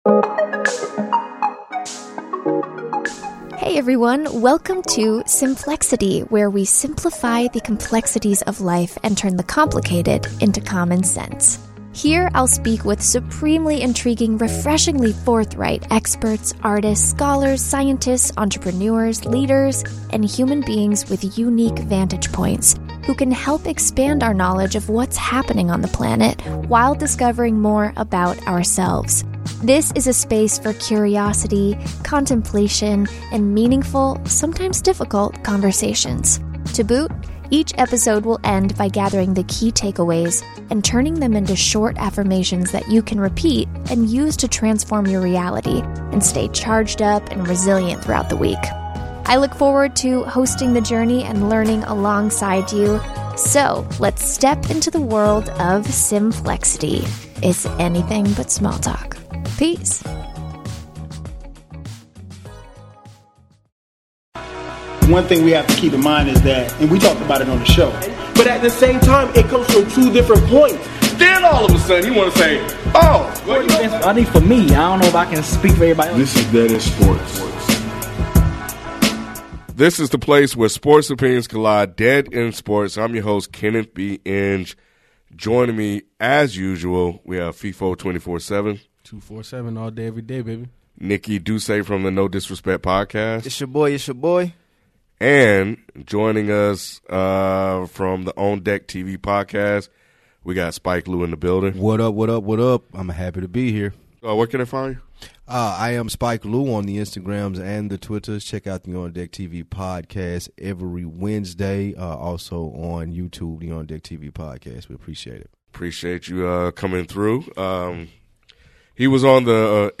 Our favorite part of recording a live podcast each week is participating in the great conversations that happen on our live chat, on social media, and in our comments section.